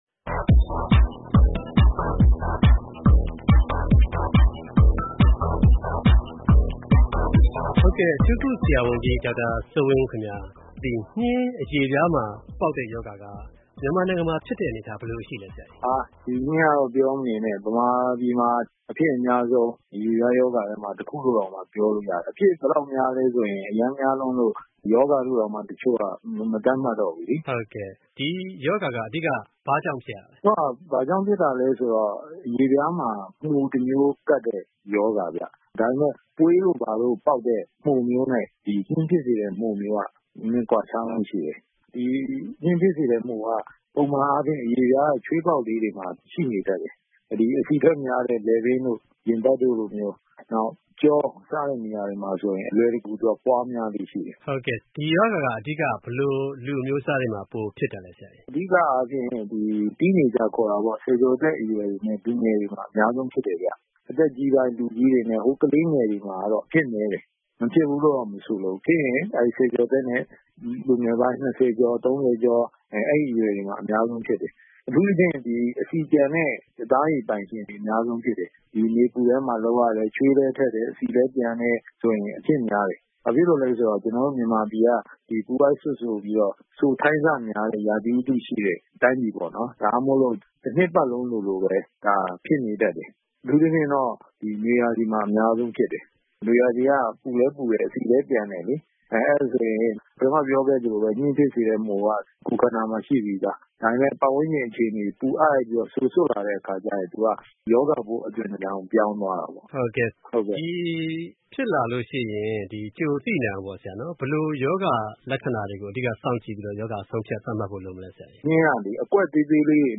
ကျန်းမာရေး